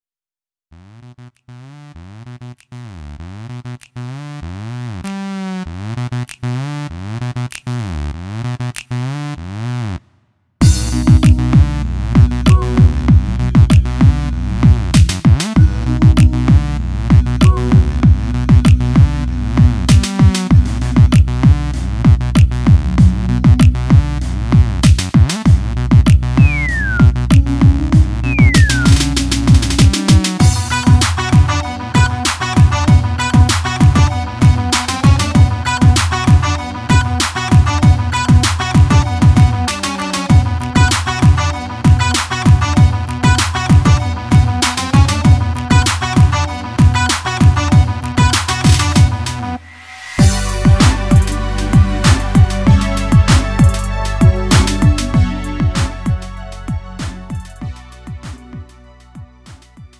음정 -1키 3:13
장르 구분 Lite MR